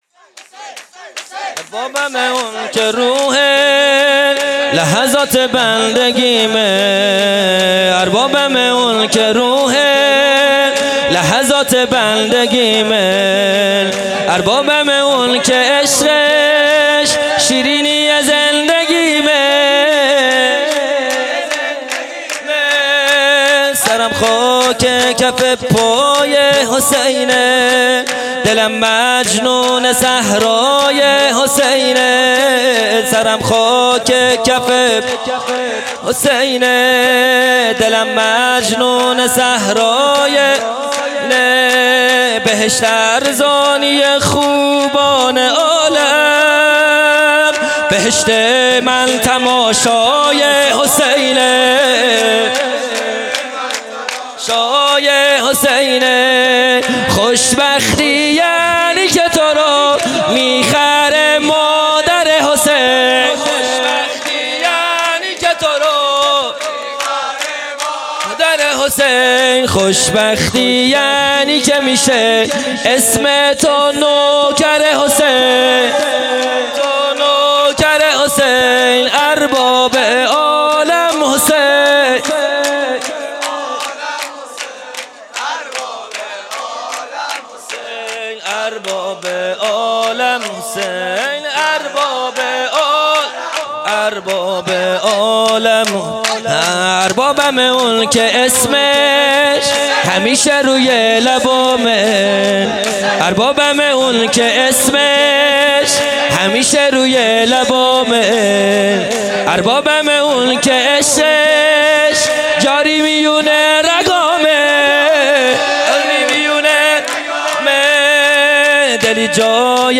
خیمه گاه - هیئت بچه های فاطمه (س) - سرود | اربابم اونکه روح لحظات بندگیمه
جلسۀ هفتگی (به مناسبت ولادت حضرت علی اکبر(ع))